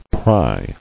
[pry]